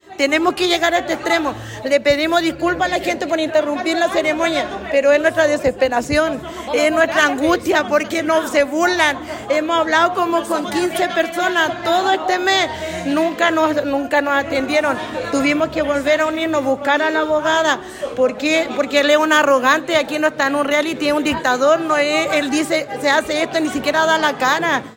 Entre abucheos de los presentes salieron las manifestantes